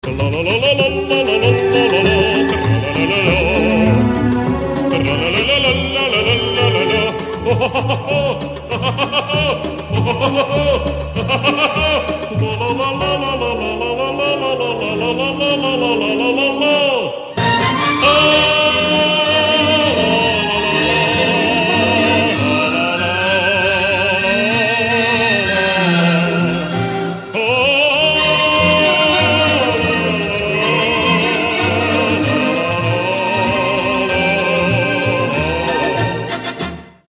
мужской вокал
смех